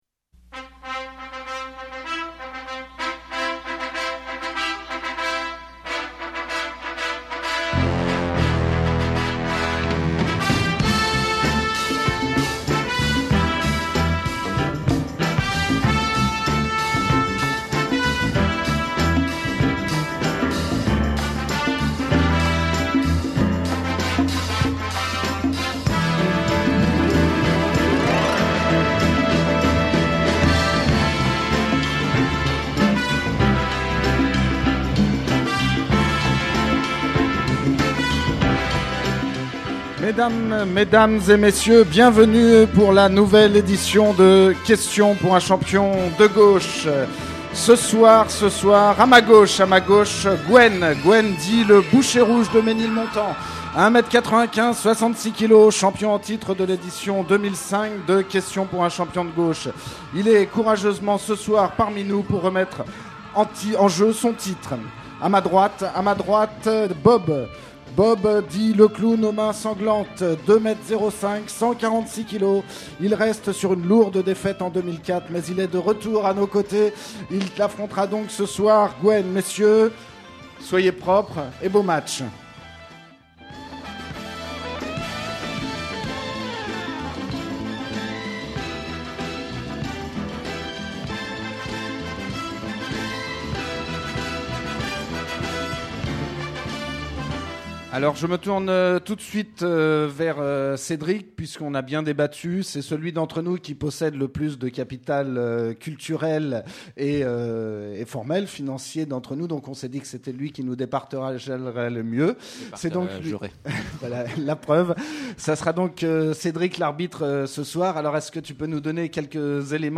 Il faisait beau, les vacances approchaient, on voulait faire la fête, alors on a décidé de jouer. Un membre de la BAC et un membre de la Ligue, venu remettre son titre en jeu, se sont sauvagement affrontés pour répondre à nos questions impitoyables sur le budget de la bourgeoisie et autres citations de grands hommes.